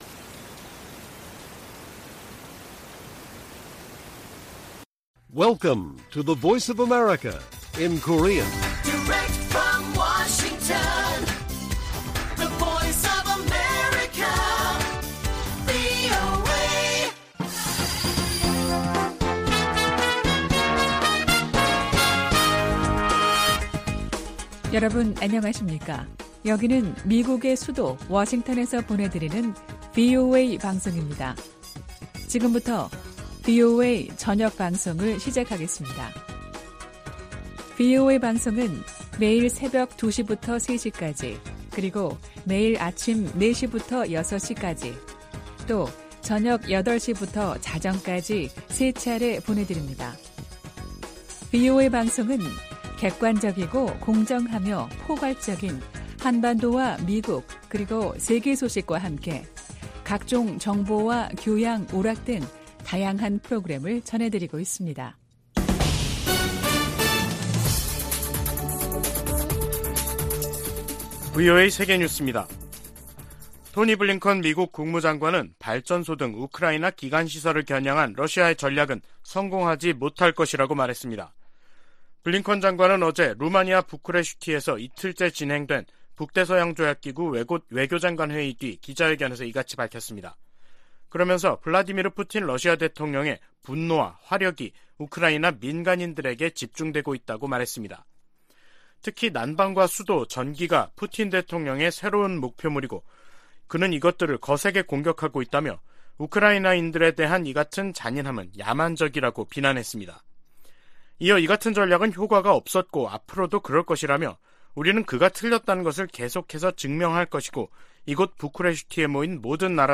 VOA 한국어 간판 뉴스 프로그램 '뉴스 투데이', 2022년 12월 1일 1부 방송입니다. 바이든 행정부는 한일 동맹과의 긴밀한 공조 속에 한반도의 완전한 비핵화를 계속 추진할 것이라고 제이크 설리번 백악관 국가안보보좌관이 밝혔습니다. 북한과 중국, 러시아, 이란, 시리아 지도자들은 국내 산적한 문제에도 불구하고 국제사회에 위협을 가하는 ‘불량배와 독재자’라고 미치 맥코넬 상원 공화당 원내대표가 비난했습니다.